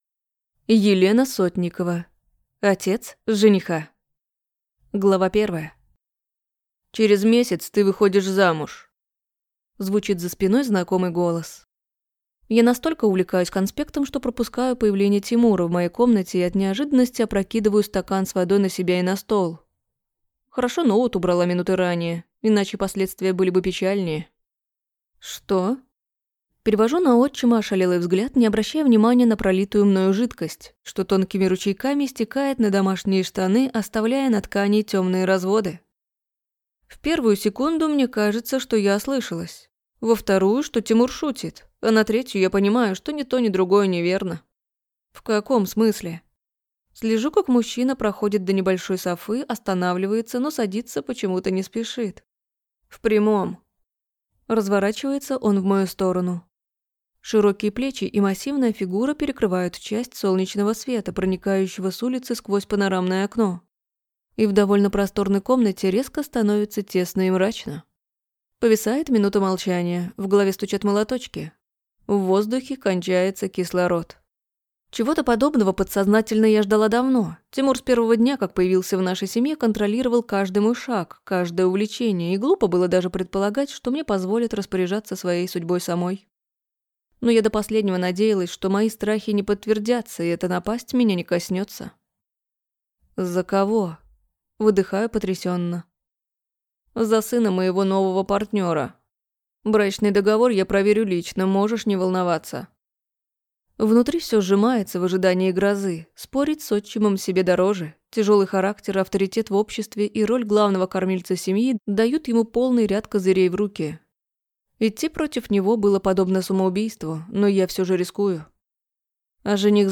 Аудиокнига Отец жениха | Библиотека аудиокниг